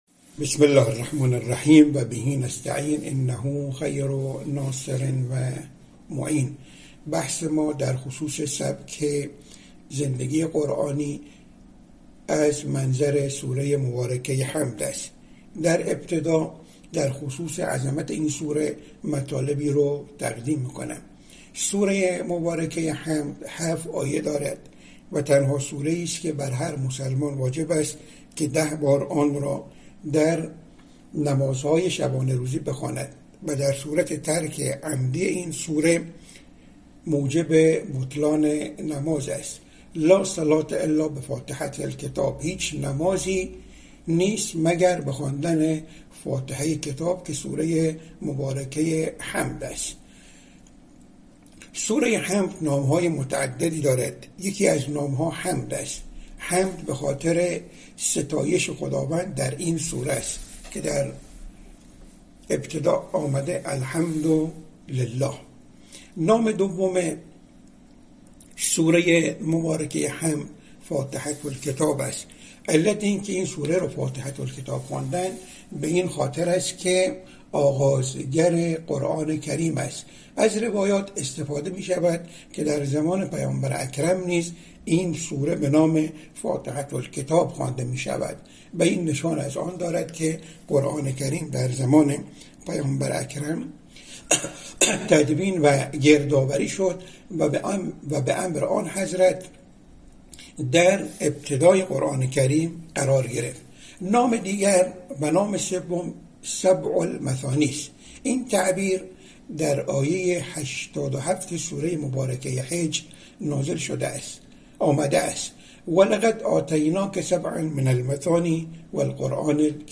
یک پژوهشگر تفسیر با بیان اینکه نام دیگر سوره حمد «سبع المثانی» است، گفت: این تعبیر در آیه 87 سوره مبارکه حجر آمده است: «وَلَقَدْ آتَيْنَاكَ سَبْعًا مِنَ الْمَثَانِي وَالْقُرْآنَ الْعَظِيمَ» در این آیه، سوره حمد، در برابر کل قرآن کریم، به‌عنوان یک موهبت به پیامبر اکرم(ص) مطرح شده است.